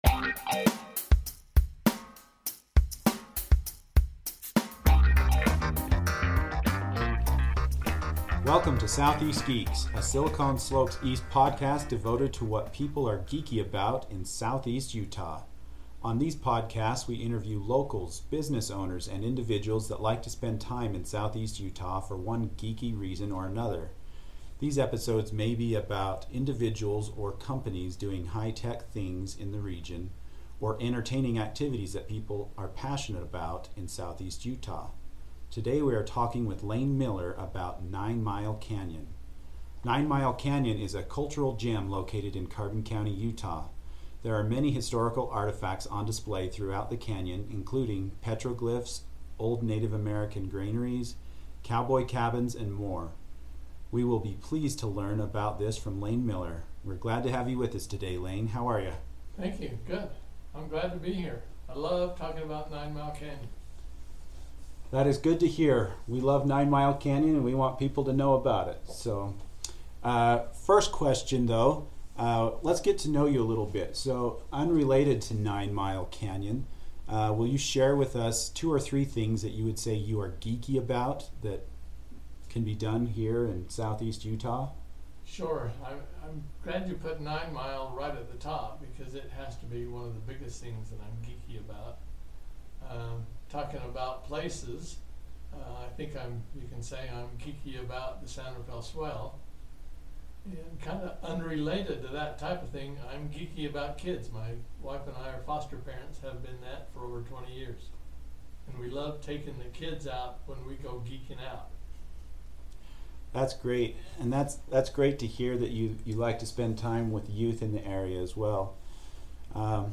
9 Mile Canyon Interview